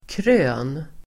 Uttal: [krö:n]